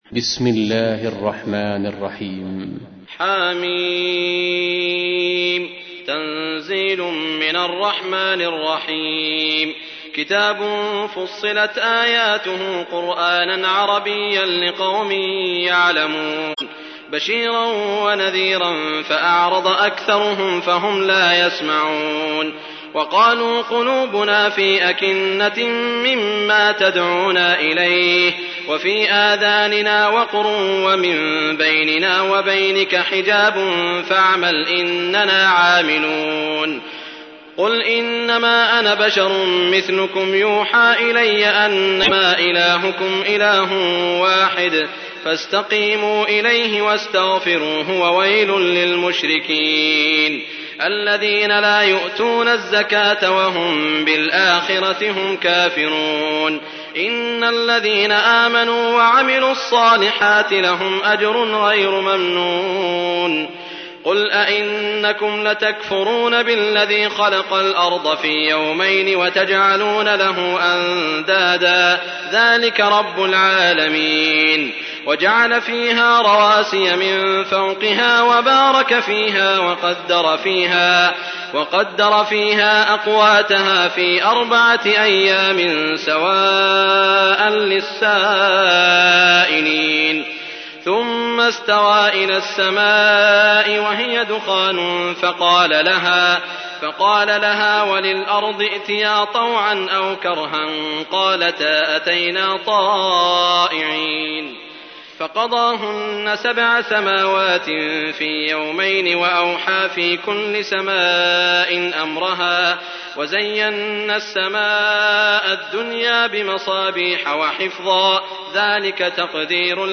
تحميل : 41. سورة فصلت / القارئ سعود الشريم / القرآن الكريم / موقع يا حسين